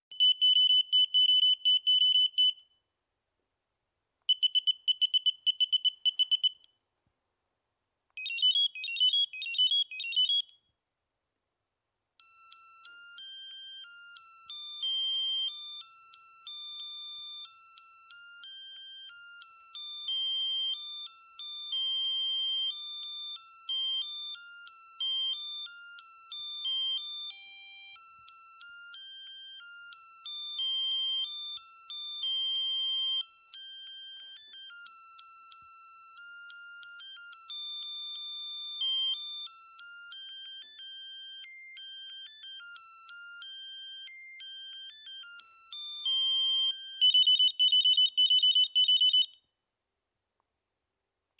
Звуки пейджера
Проверяем все мелодии в настройках пейджера